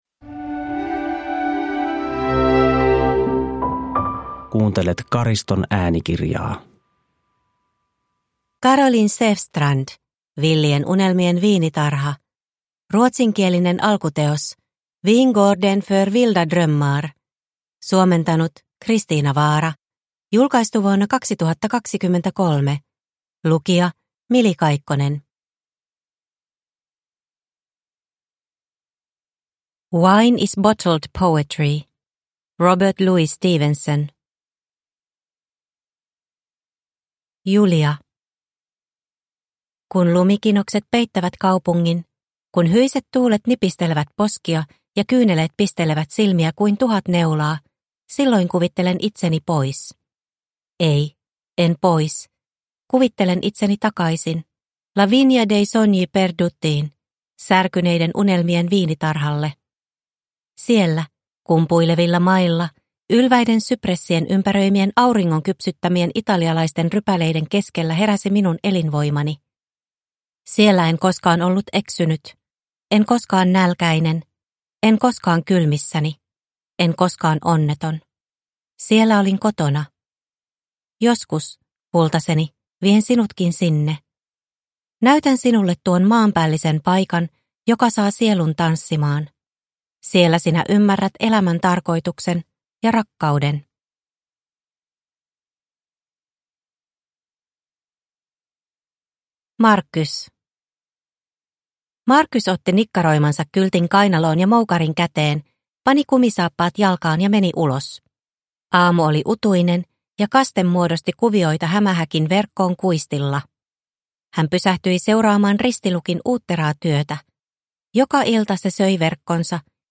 Villien unelmien viinitarha – Ljudbok – Laddas ner